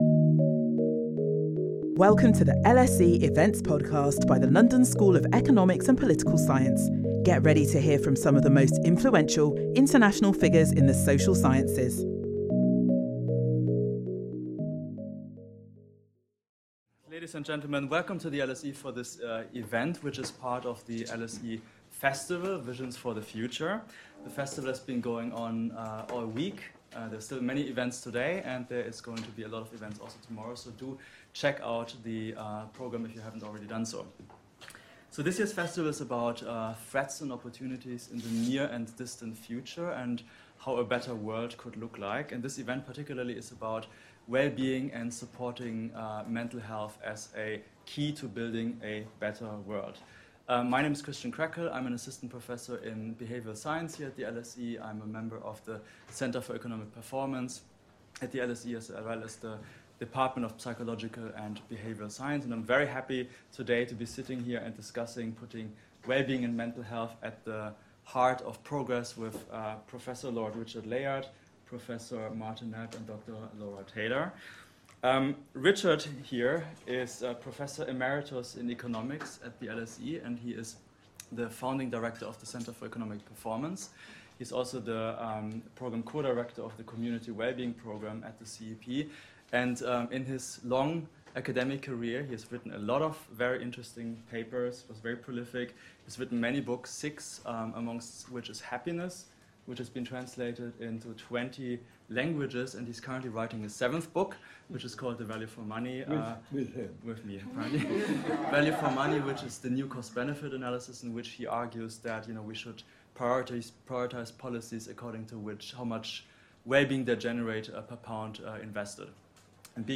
The panel explore how we can identify cost-effective policies to improve societal wellbeing — and why it will be key to shaping the future of the UK and beyond.